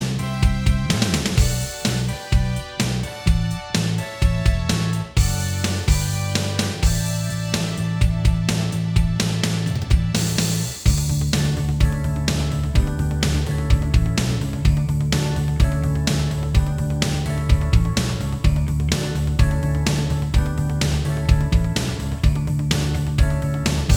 Minus All Guitars Rock 3:54 Buy £1.50